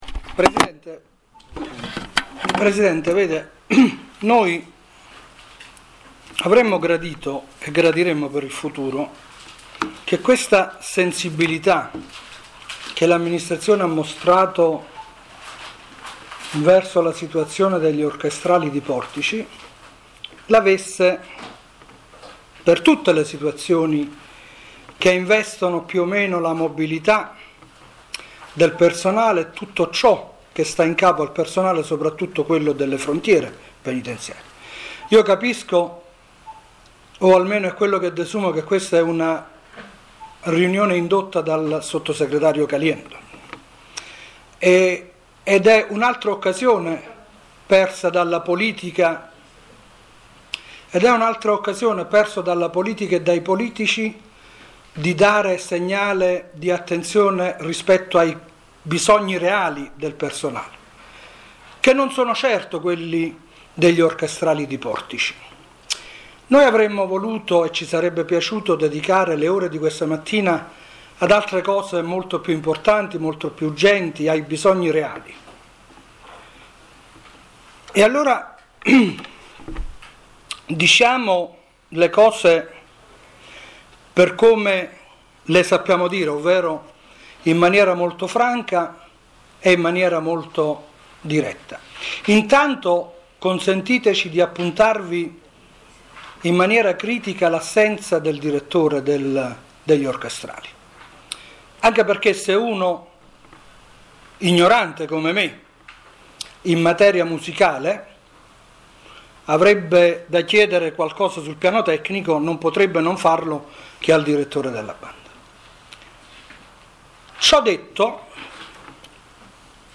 08.11.11 - Banda e 163° Corso- Incontro al DAP.